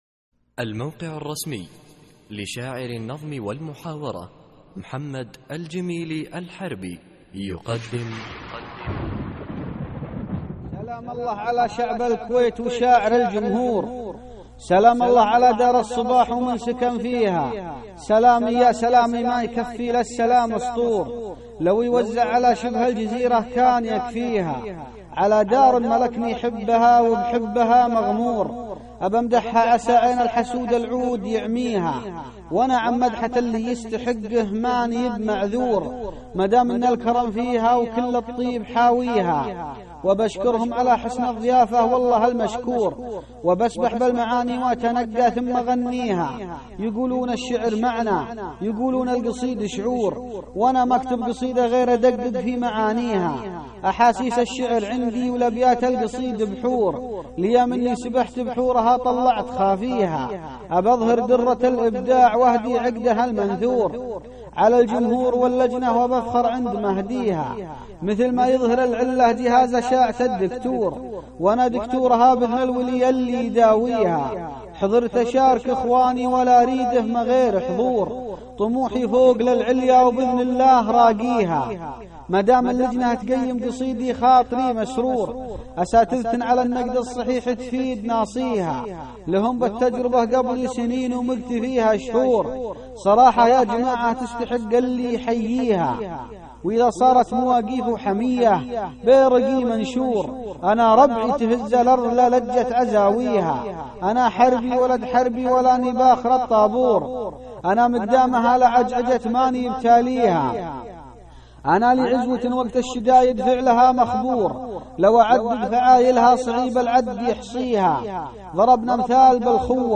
القصـائــد الصوتية